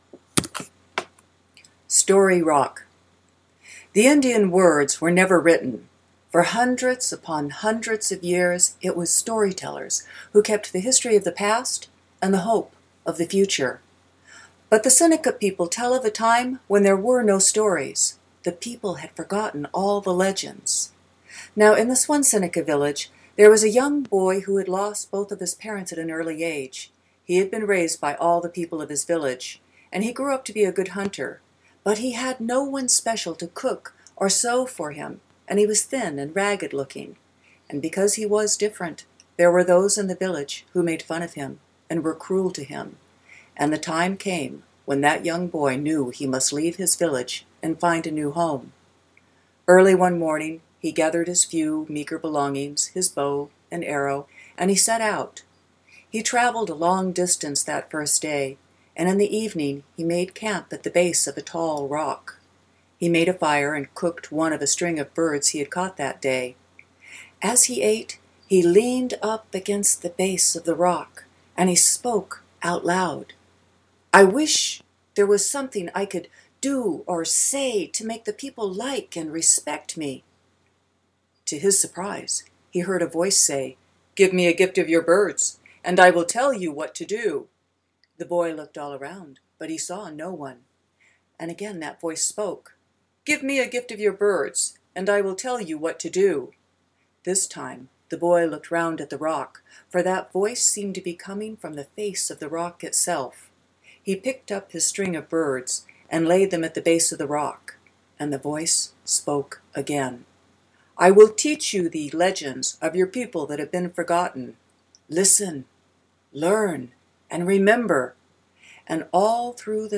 Story Summary: (Native American Tale: 4 minutes) In this Seneca legend, a young boy saves the forgotten stories of his people.